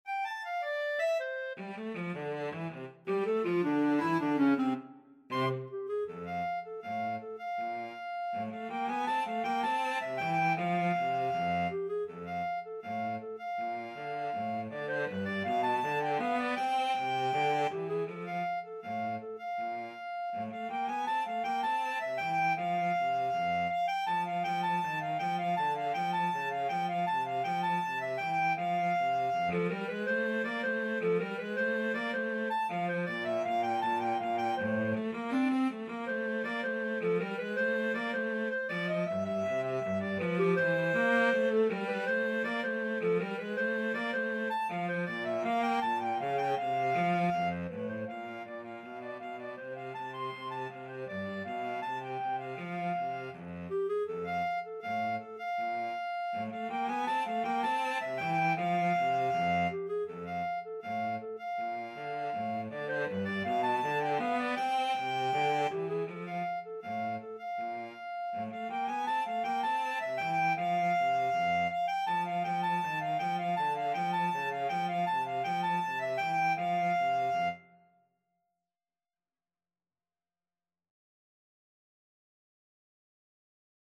Free Sheet music for Clarinet-Cello Duet
F major (Sounding Pitch) G major (Clarinet in Bb) (View more F major Music for Clarinet-Cello Duet )
=180 Presto (View more music marked Presto)
4/4 (View more 4/4 Music)
Jazz (View more Jazz Clarinet-Cello Duet Music)